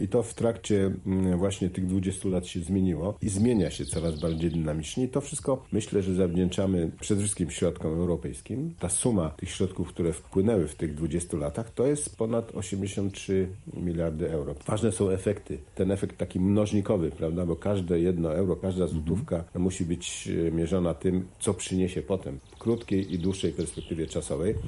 Podkarpacie przez ostatnie 20 lat bardzo się rozwinęło – powiedział w audycji „Tu i teraz” marszałek województwa podkarpackiego Władysław Ortyl.